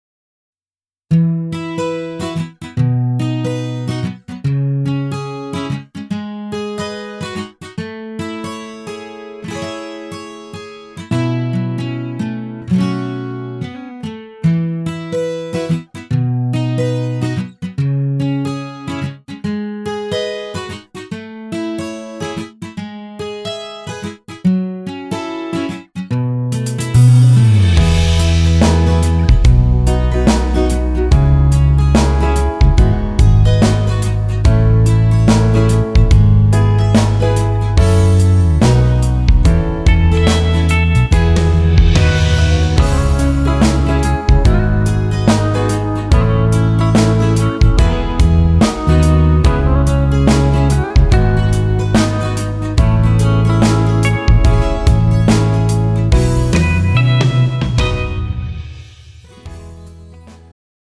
음정 여자-1키
장르 축가 구분 Pro MR